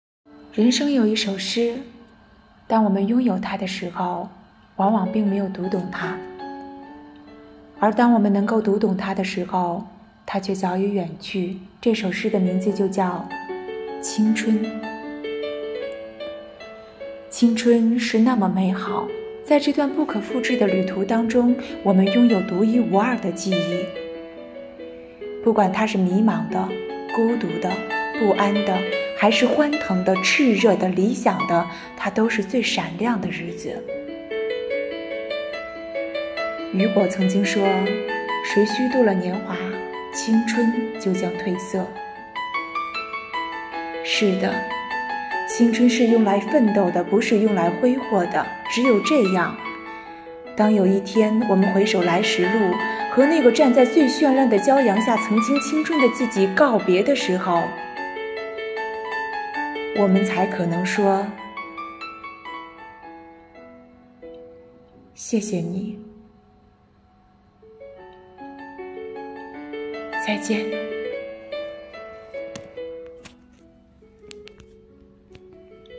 在五一劳动节、五四青年节来临之际，市纪委监委宣传部、机关党委组织青年干部，以“奋进新征程筑梦新时代”为主题，以朗诵为载体，用诗篇来明志，抒发对祖国的热爱、对梦想的执着、对青春的礼赞。